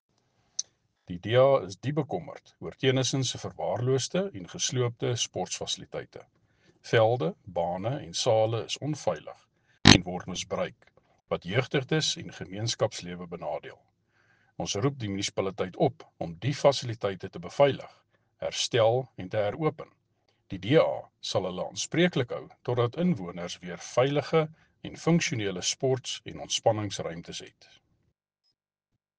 Afrikaans soundbites by Cllr Andre Kruger and